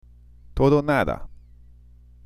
真ん中の「o」を抜かして聞かれた方が多かったですね＾＾。
確かにくっついて聞こえますから難しいですし、